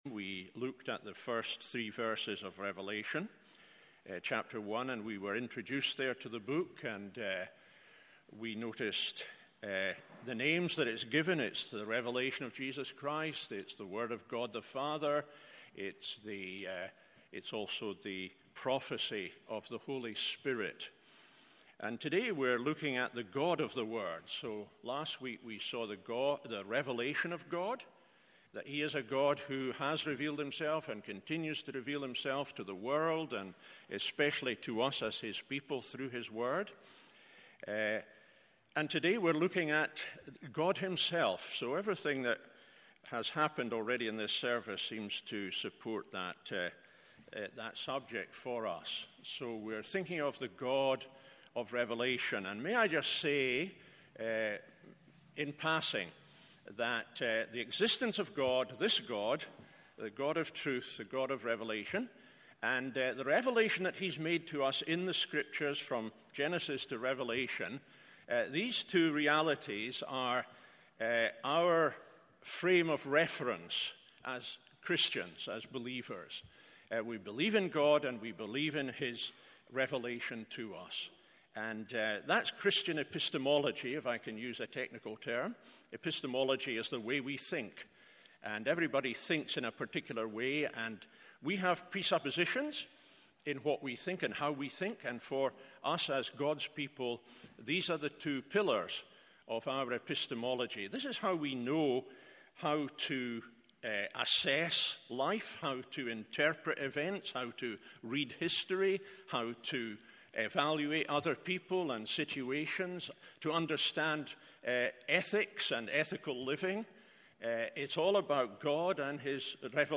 MORNING SERVICE Rev 1:4-8…